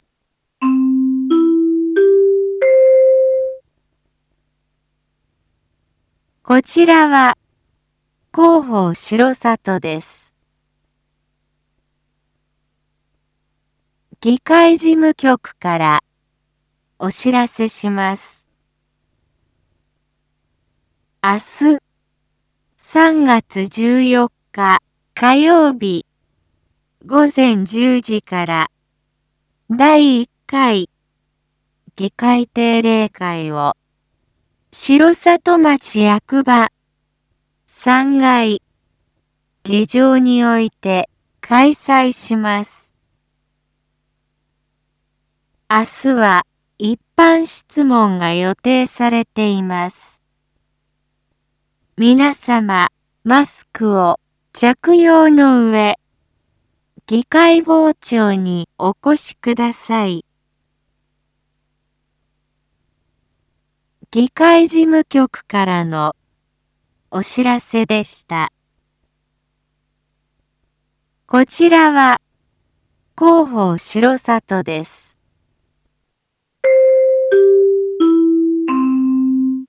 一般放送情報
Back Home 一般放送情報 音声放送 再生 一般放送情報 登録日時：2023-03-13 19:01:21 タイトル：R5.3.13 19時放送分 インフォメーション：こちらは広報しろさとです。